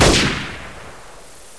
scout_fire-1.wav